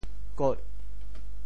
“挾”字用潮州话怎么说？